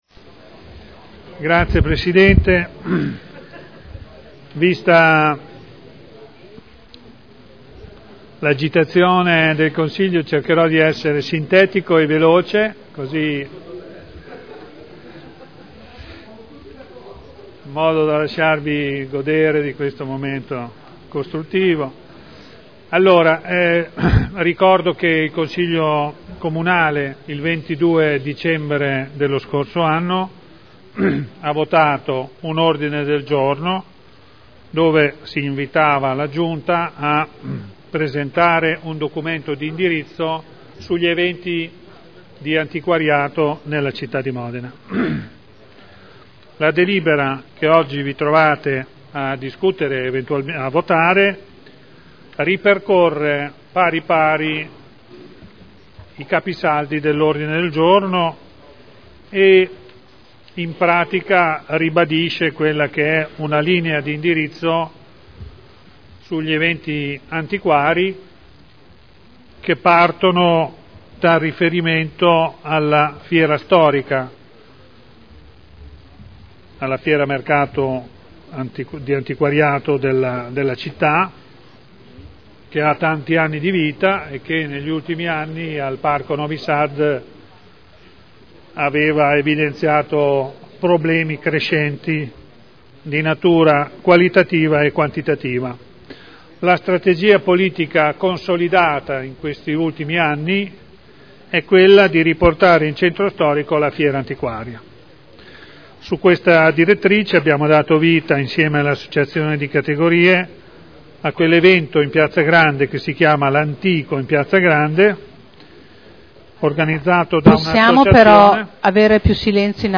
Graziano Pini — Sito Audio Consiglio Comunale